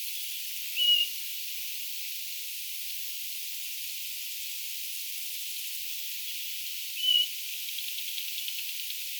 Äänestä määritettynä tämä on amerikankurmitsa.
amerikankurmitsan ääntä
Äänitetty taivaanlammasniityn lintutornista.
onko_amerikankurmitsa_vaiko_ihan_tavallinen_kapustarinta_maarita.mp3